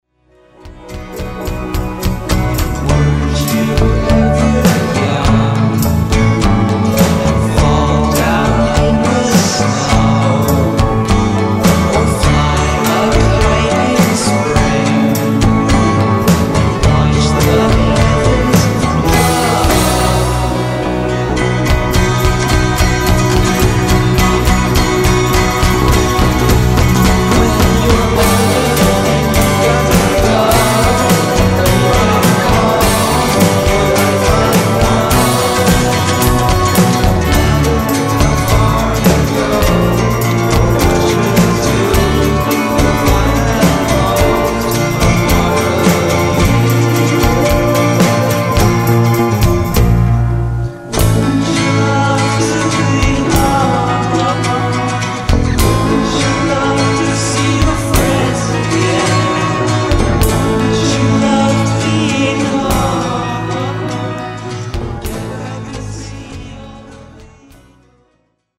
lead vocals, 12-string guitar and piano